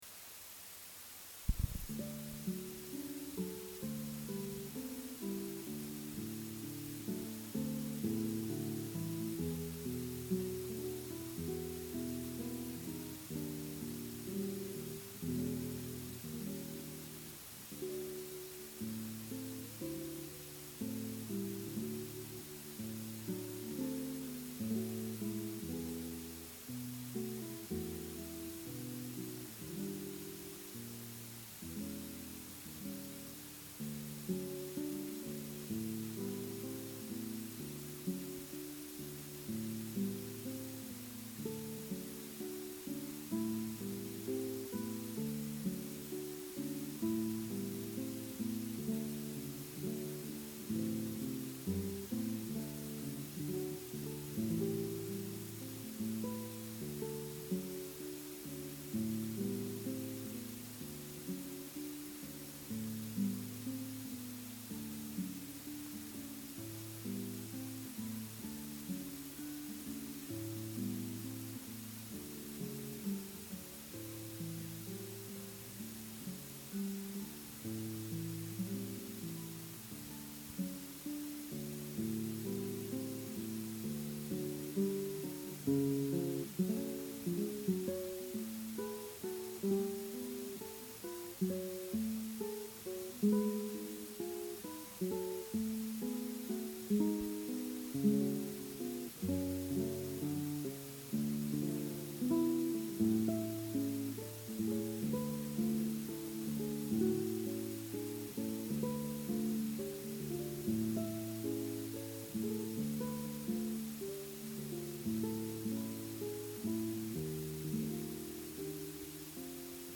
Home recordings